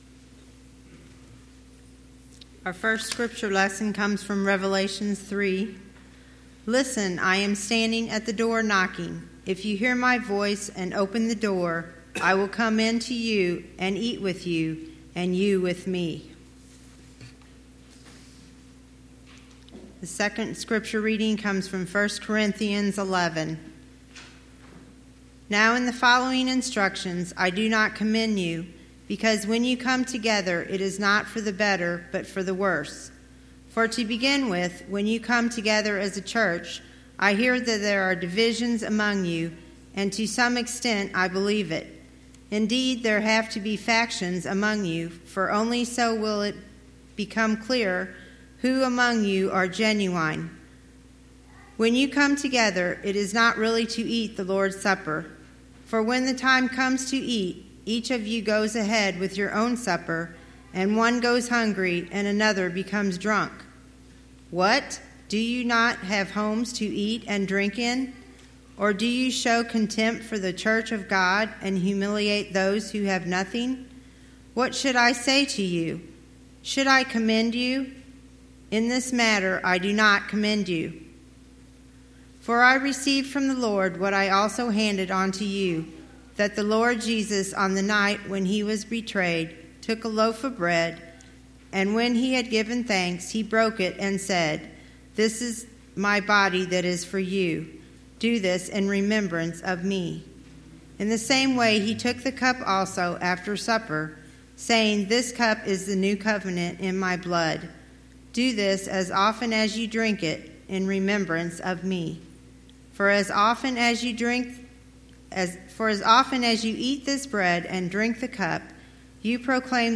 Hero or Heretic Passage: Revelation 3:20; 1 Corinthians 11:17-34 Service Type: Sunday Morning « Paul